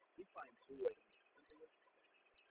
描述：重建信号0dB
标签： 0分贝 SNR 重构
声道立体声